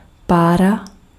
Ääntäminen
US Tuntematon aksentti: IPA : /stiːm/